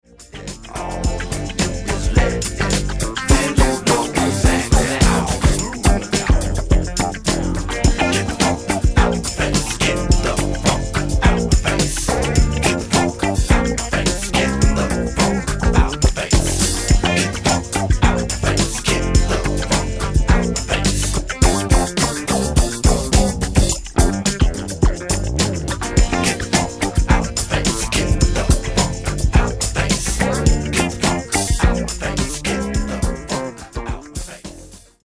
Genere:   Disco | Funky